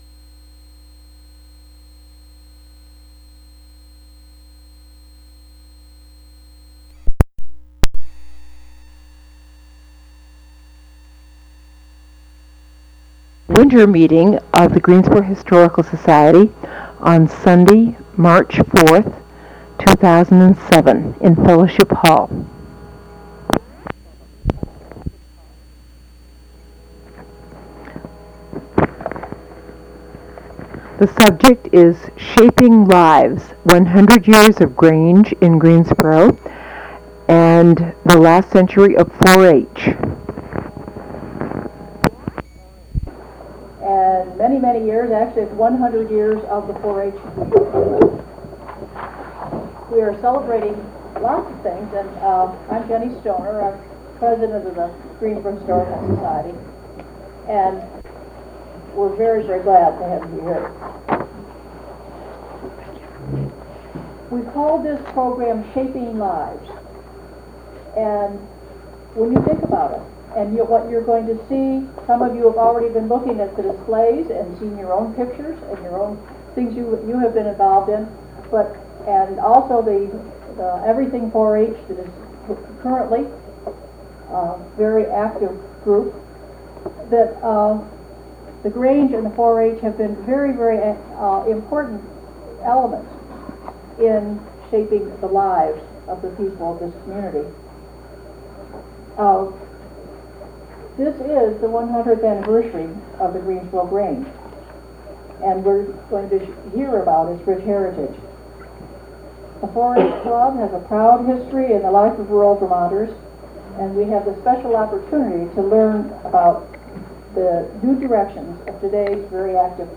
Audio Archive GHS Annual Meeting of March 4, 2007 Discussion of the Grange and 4H This is an audio tape made during the annual meeting of the Greensboro Historical Society on March 4, 2007.
Unfortunately, the sound quality is not uniformly good. At times the sound level is quite low. There is considerable background and tape noise throughout.